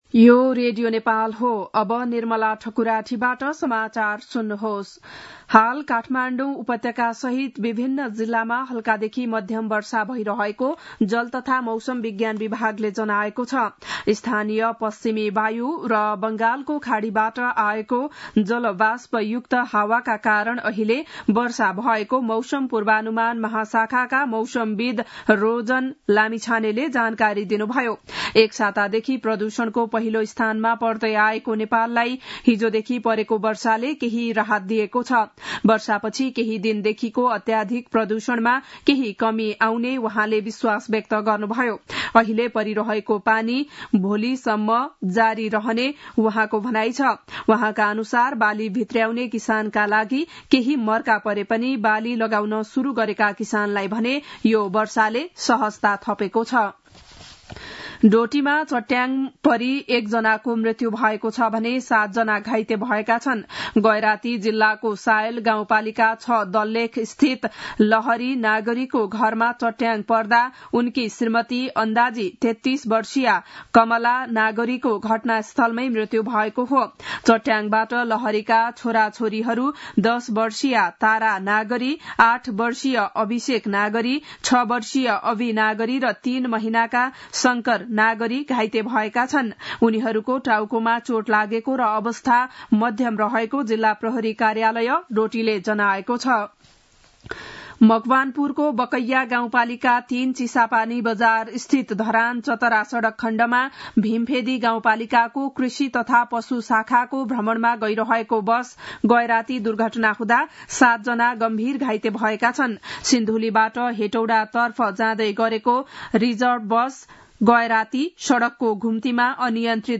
बिहान ११ बजेको नेपाली समाचार : २८ चैत , २०८१
11-am-news-1-3.mp3